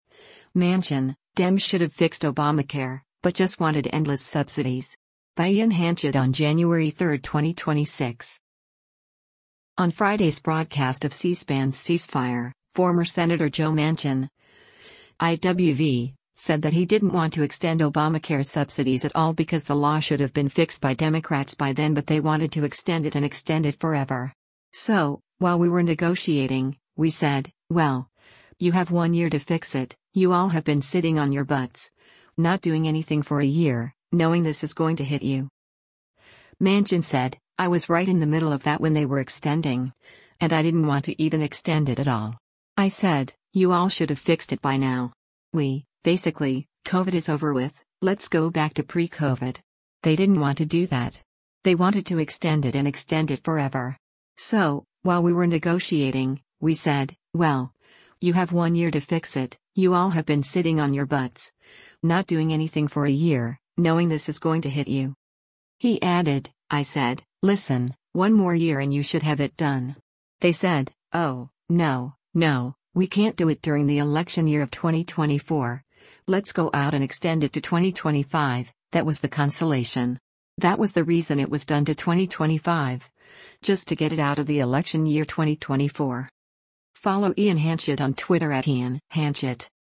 On Friday’s broadcast of C-SPAN’s “Ceasefire,” former Sen. Joe Manchin (I-WV) said that he didn’t want to extend Obamacare subsidies at all because the law should have been fixed by Democrats by then but “They wanted to extend it and extend it forever. So, while we were negotiating, we said, well, you have one year to fix it, you all have been sitting on your butts, not doing anything for a year, knowing this is going to hit you.”